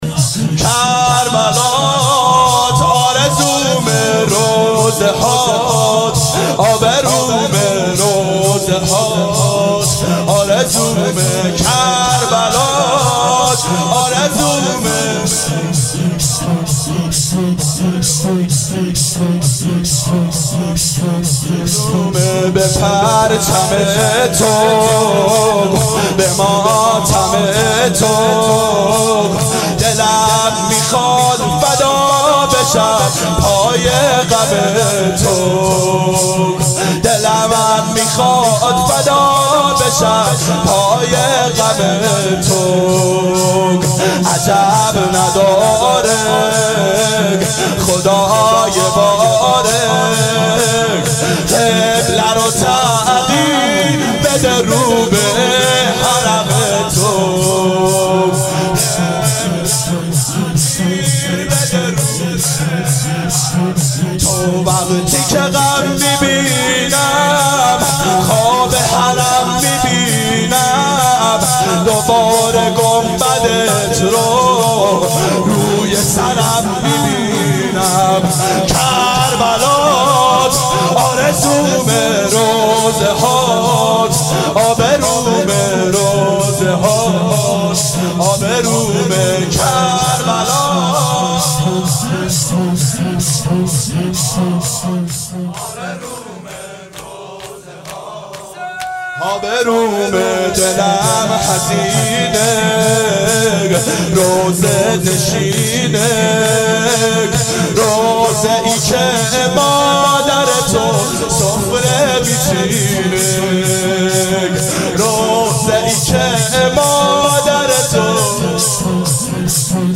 دهه اول صفر سال 1390 هیئت شیفتگان حضرت رقیه س شب سوم (شام غریبان)